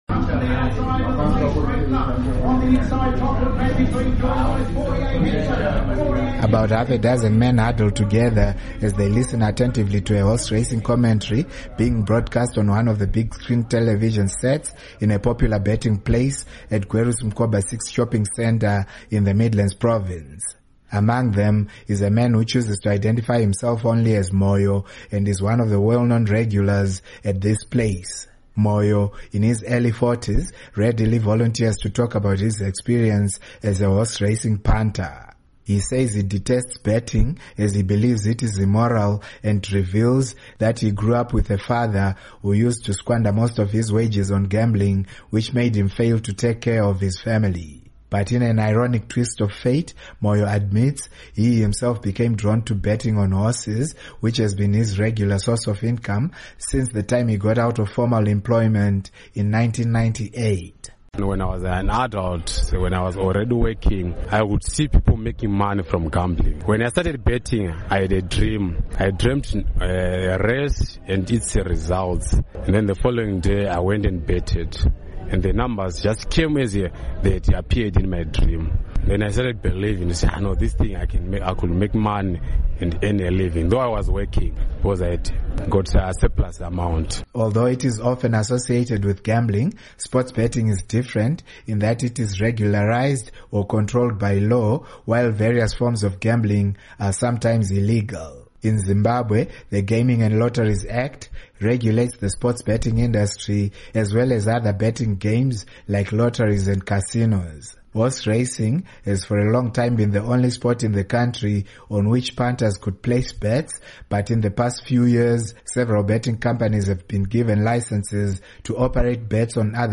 Report on Sport Gambling